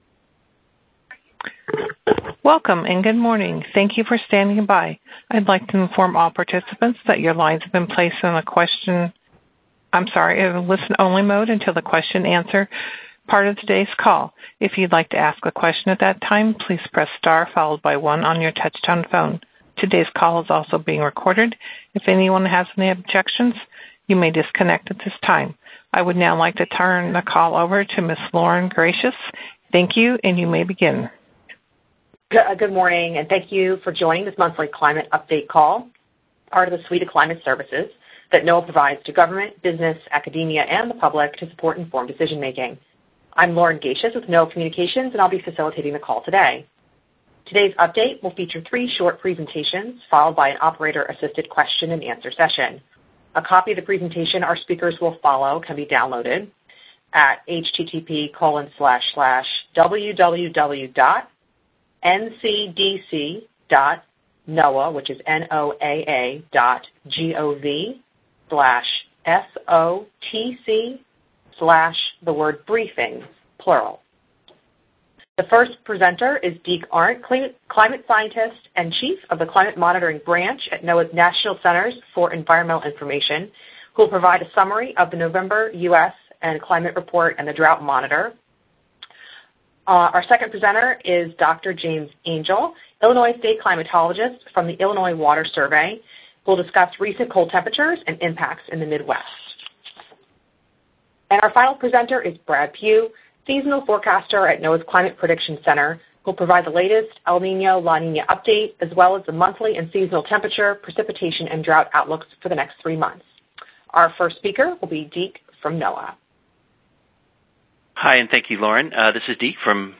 Experts recap November and provide outlooks through March